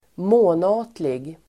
Ladda ner uttalet
månatlig adjektiv, monthly Uttal: [mån'a:tlig] Böjningar: månatligt, månatliga Synonymer: månadsvis Definition: som sker varje månad monthly adjektiv, månatlig , månads- Förklaring: Appearing or happening once a month.